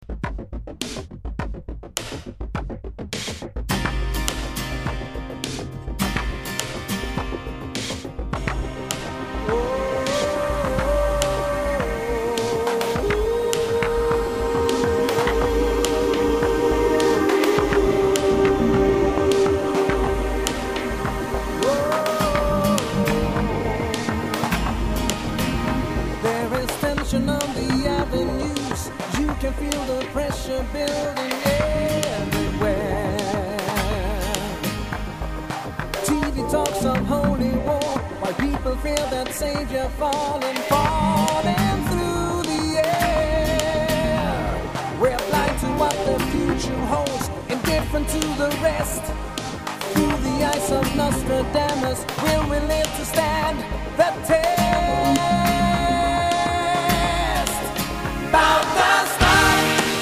keyboards, bass, guitars
lead and background vocals
drums
acoustic guitar
guitar solos
trumpet
saxophones